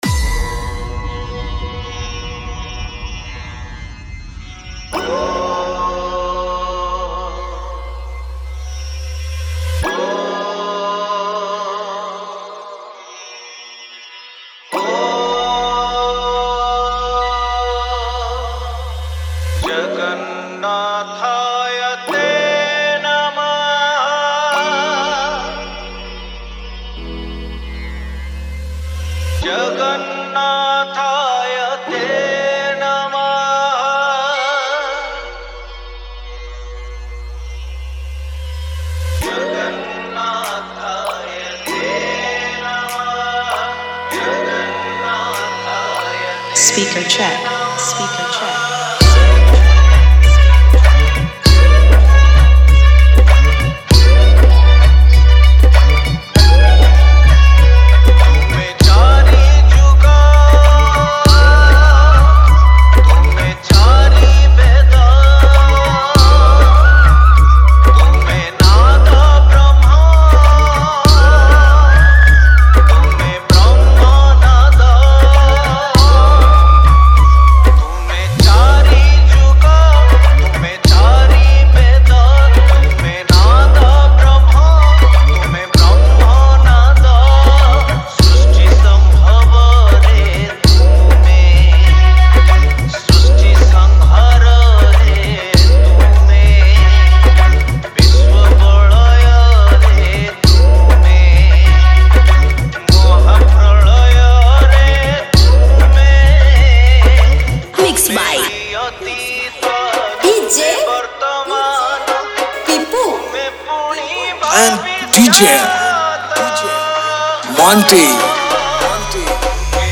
Bhajan Dj Song